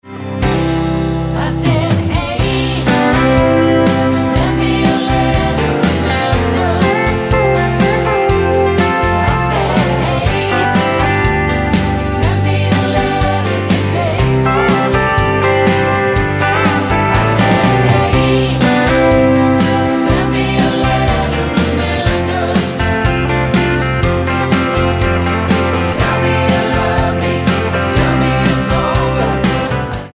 Tonart:C-D mit Chor